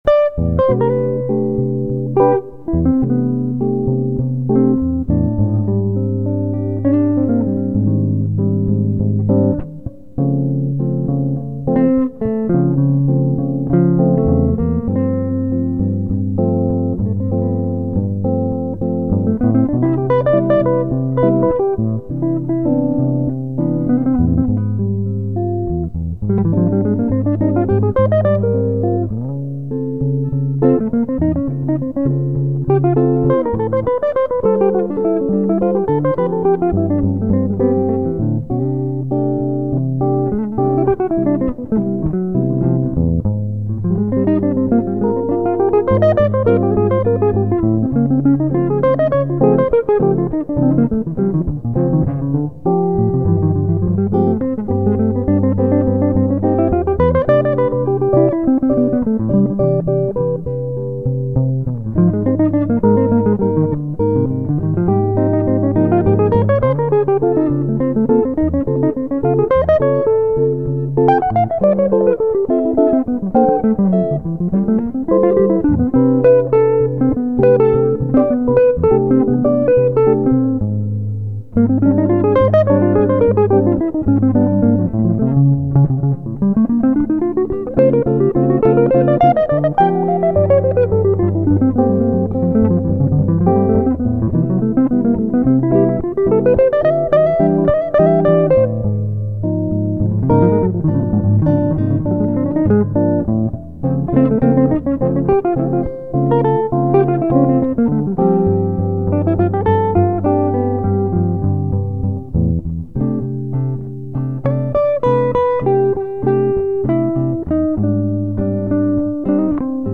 JAZZGITARREN – WORKSHOP
WORKSHOP - BEISPIELE
[1] MINOR TURNAROUND